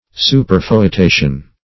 Superfoetation \Su`per*foe*ta"tion\, n.